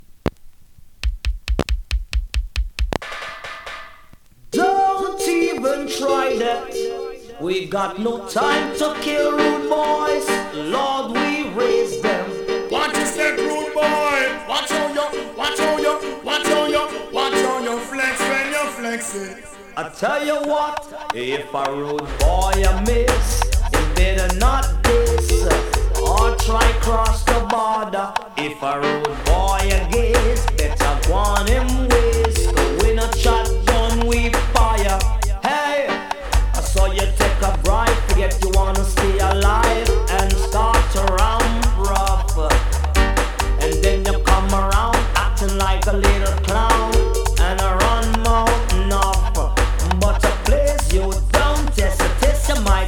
2025 NEW IN!! DANCEHALL!!
スリキズ、ノイズかなり少なめの